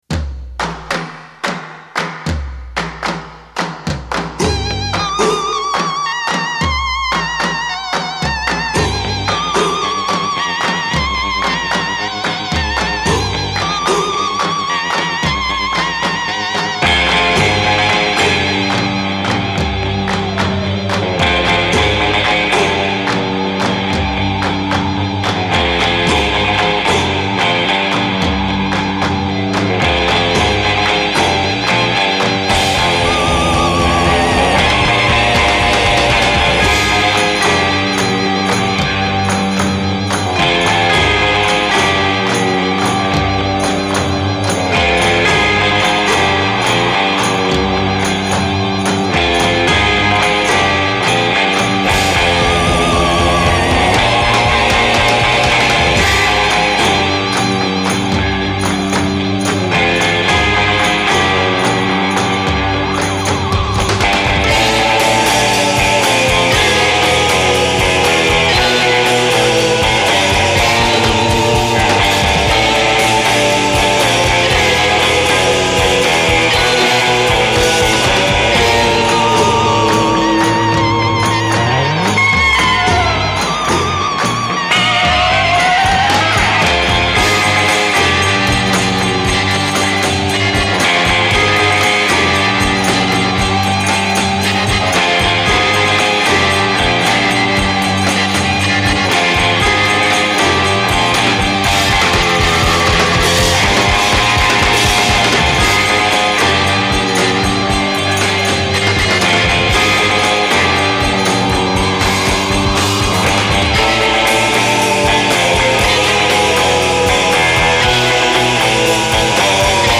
Danish surf-rockers